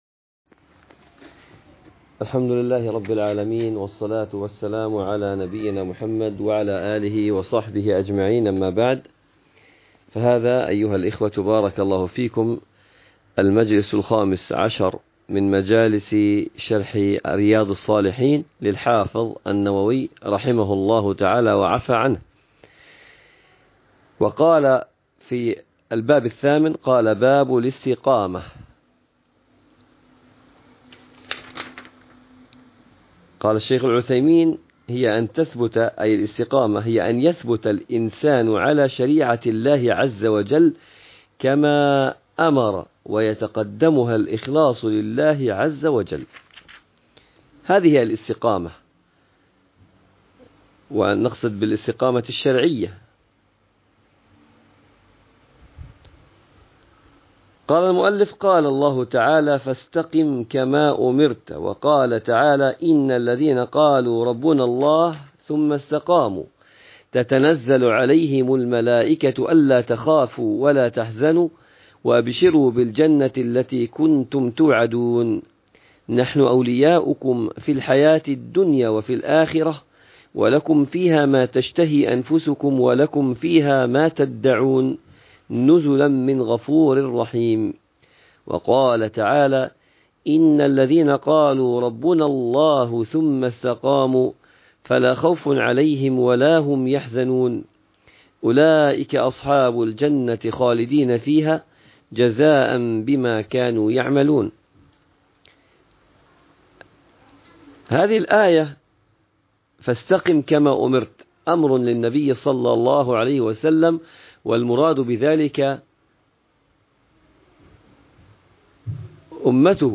شرح رياض الصالحين الدرس 15 باب الاستقامة وباب التفكير في مخلوقات الله جل جلاله